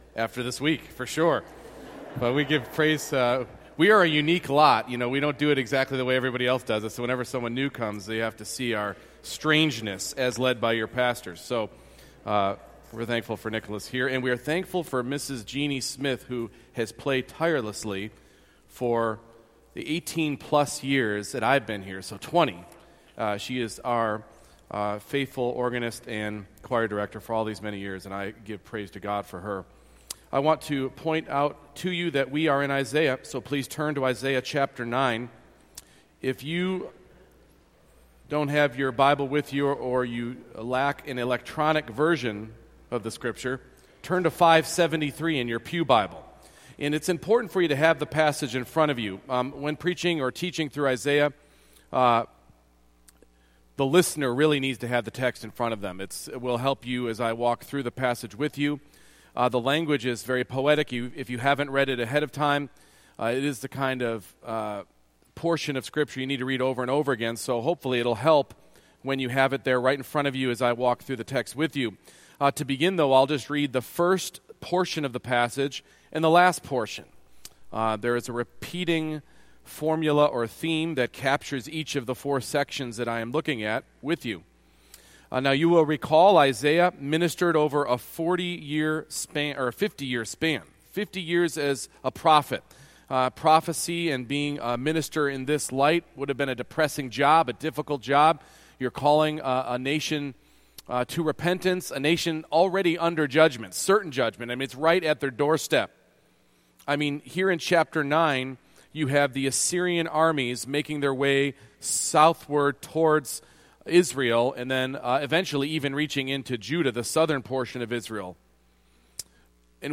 Isaiah 9:8-10:4 Service Type: Morning Worship It might be said that pride is the root of all sin.